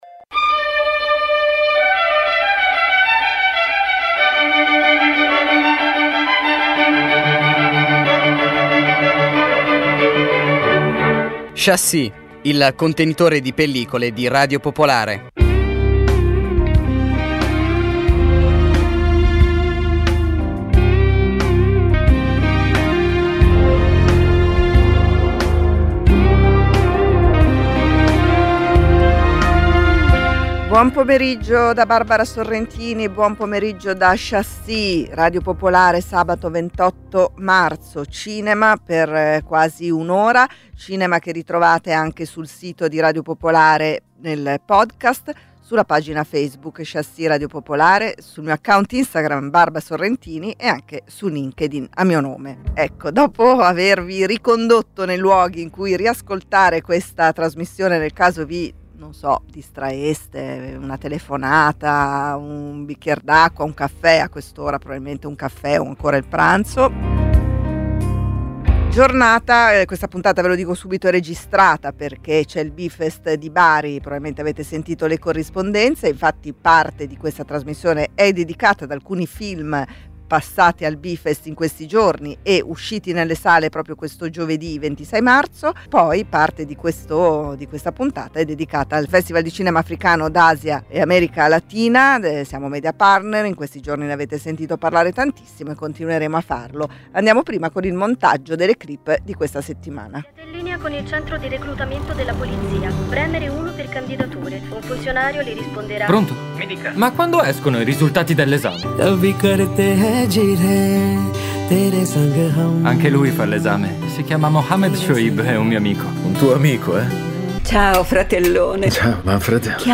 Ogni sabato offre un'ora di interviste con registi, attori, autori, e critici, alternando parole e musica per evocare emozioni e riflessioni cinematografiche. Include notizie sulle uscite settimanali, cronache dai festival e novità editoriali. La puntata si conclude con una canzone tratta da colonne sonore.